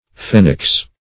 phenix - definition of phenix - synonyms, pronunciation, spelling from Free Dictionary
Phenix \Phe"nix\, n.; pl. Phenixes. [L. phoenix, Gr. foi^nix.]